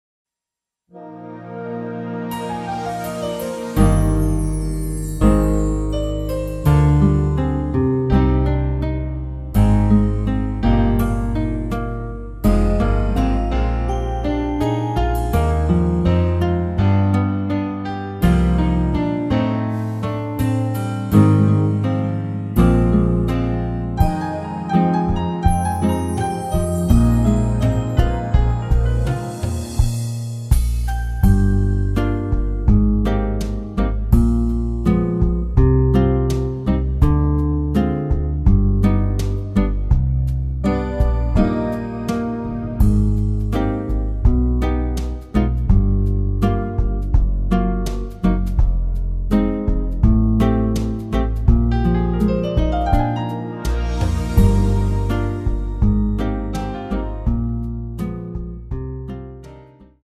Eb
◈ 곡명 옆 (-1)은 반음 내림, (+1)은 반음 올림 입니다.
앞부분30초, 뒷부분30초씩 편집해서 올려 드리고 있습니다.